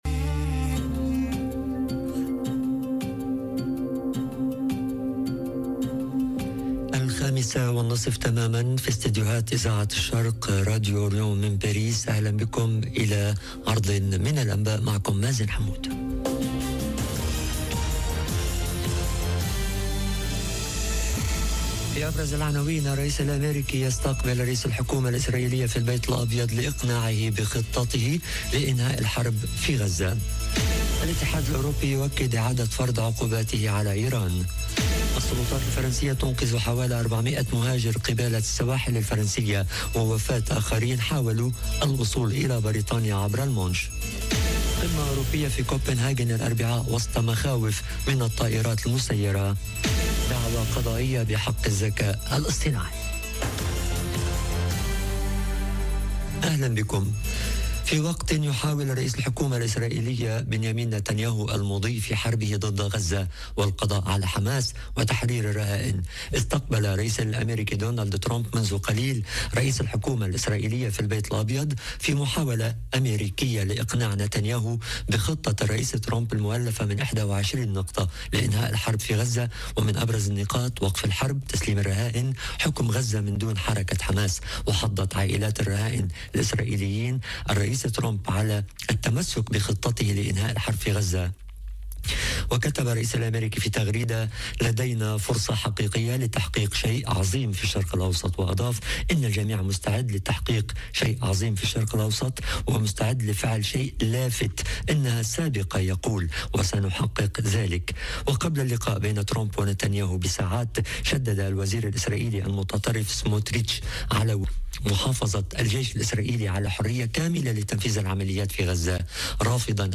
نشرة أخبار المساء: الرئيس الأميركي يستقبل رئيس الحكومة الإسرائيلية لإقناعه بخطته لإنهاء الحرب في غزة - Radio ORIENT، إذاعة الشرق من باريس